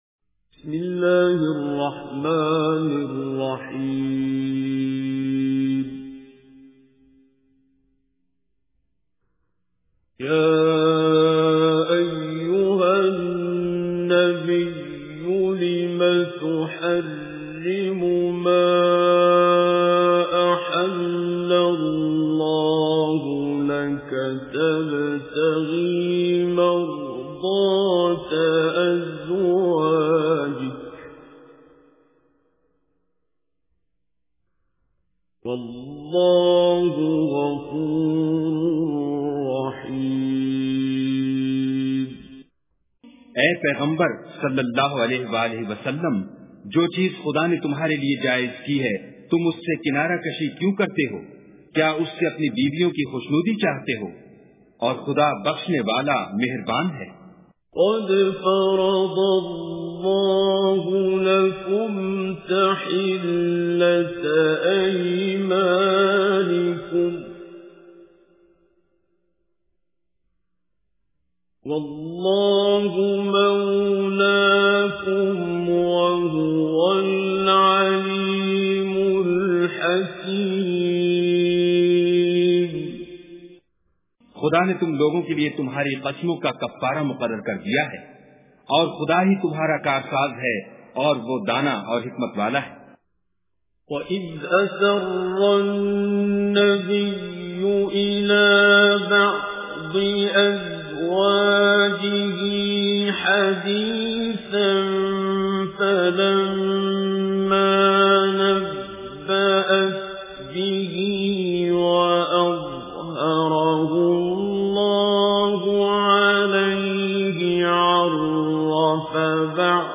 Surah Tahrim Recitation with Urdu Translation
Surah Tahrim is 66th Surah or Chapter of Holy Quran. Listen online and download mp3 recitation / tilawat of Surah Tahrim in the voice of Qari Abdul Basit As Samad.